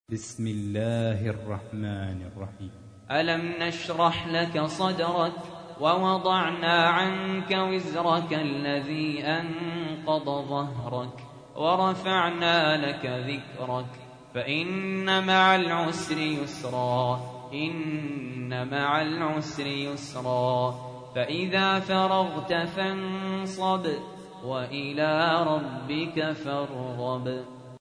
تحميل : 94. سورة الشرح / القارئ سهل ياسين / القرآن الكريم / موقع يا حسين